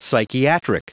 Prononciation du mot psychiatric en anglais (fichier audio)